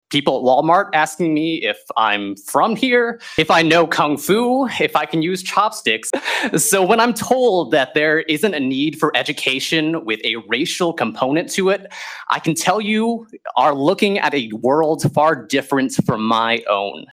A packed crowd during Wednesday’s USD 383 meeting for public comment in regards to culturally responsive teaching and learning training.